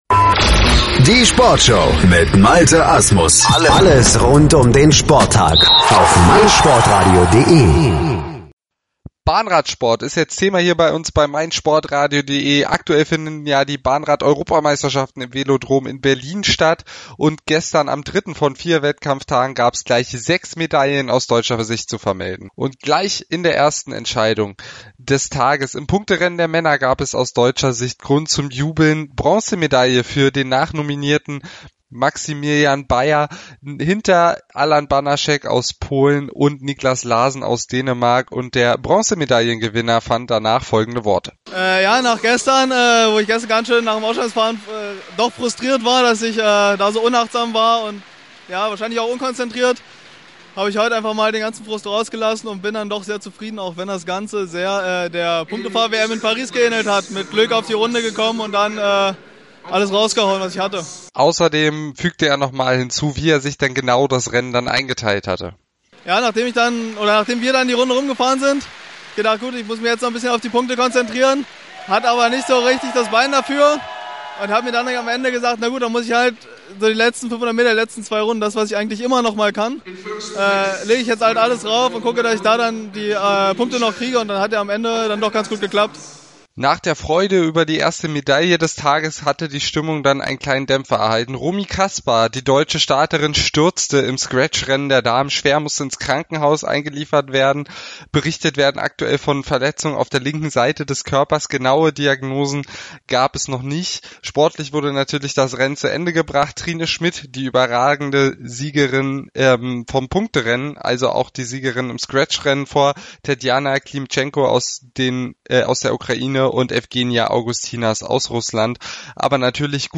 gewesen, hat Stimmen und Emotionen gesammelt und fast den Tag